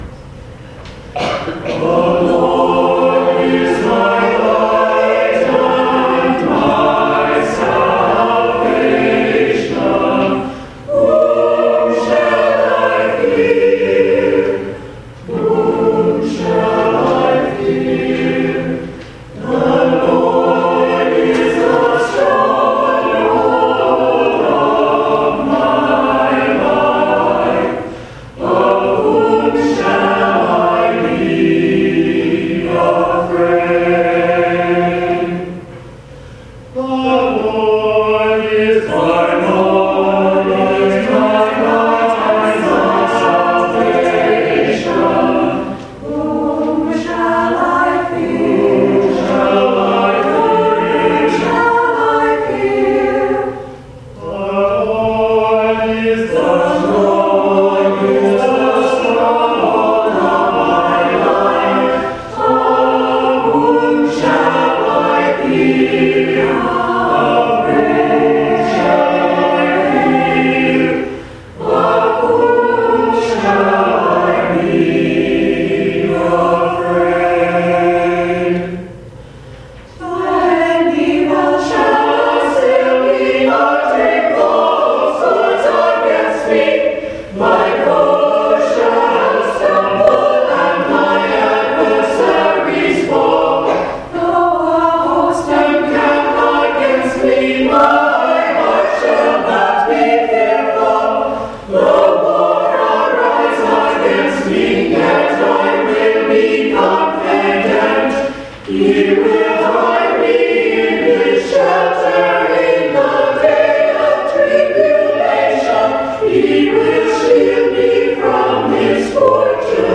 MCC Senior Choir Anthem December 14, 2014